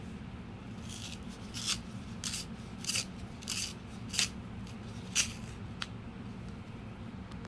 Pencil Shavings
The scritch of a pencil being sharpened, or a fountain pen on paper,